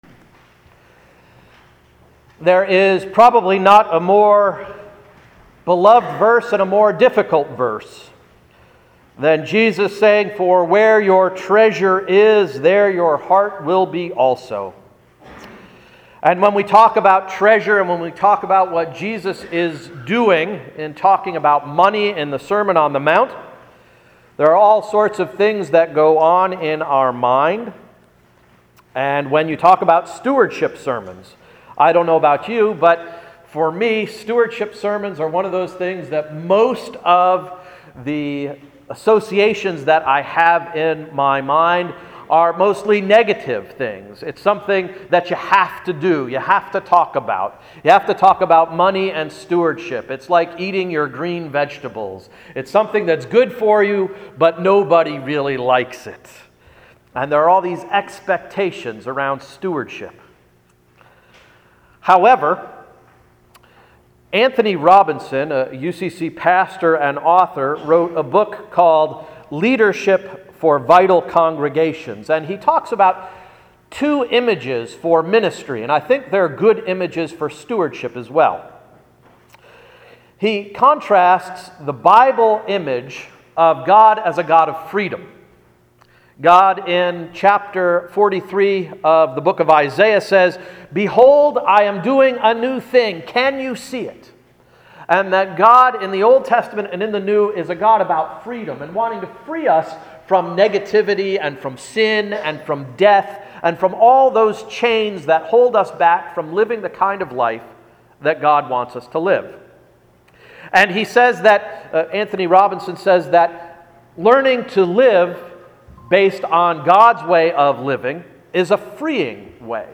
Sermon of May 12–“Treasure”